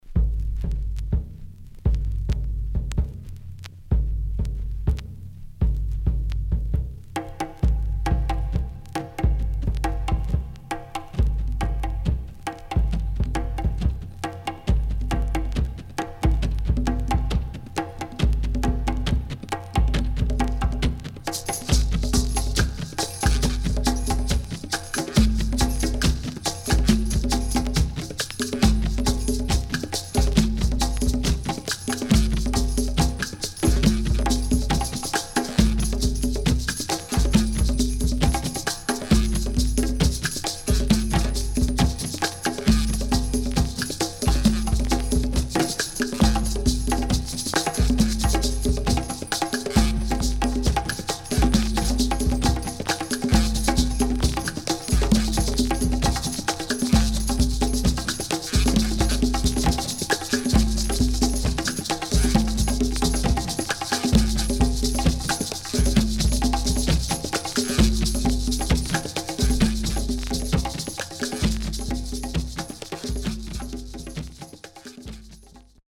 SIDE A:所々チリノイズがあり、少しプチパチノイズ入ります。